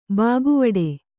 ♪ bāguvaḍe